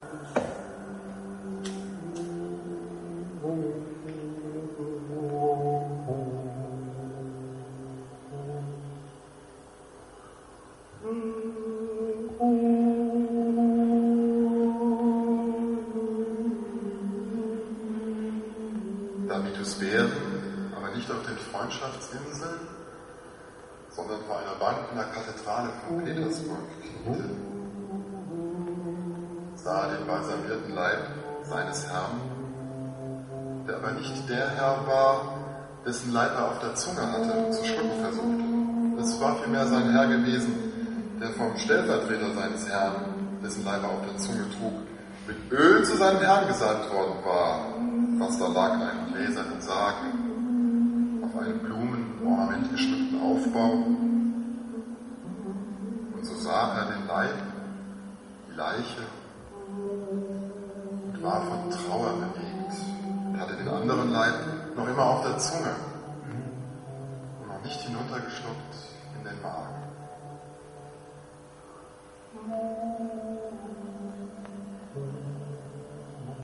Live Auftritt in der Galerie 'Aujour d'hui', Berlin Mitte am Mundwerk
Live Hörspiel